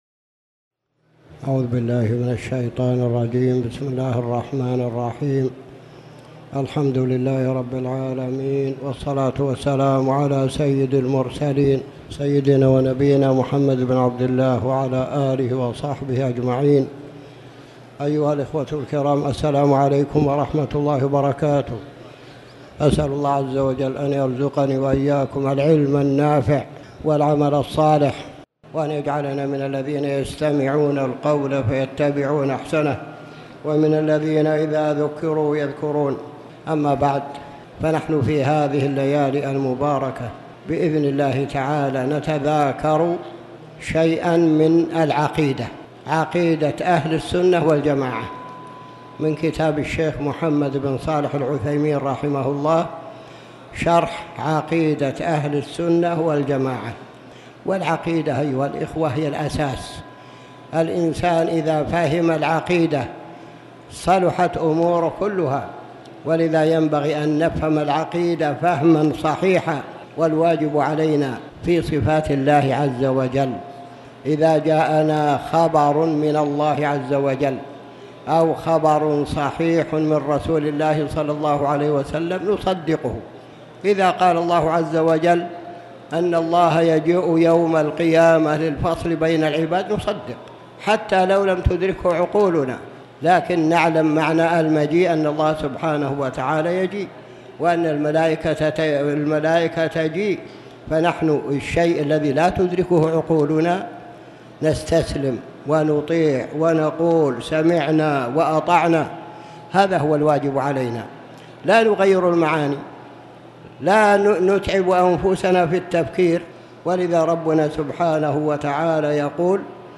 تاريخ النشر ٥ صفر ١٤٣٩ هـ المكان: المسجد الحرام الشيخ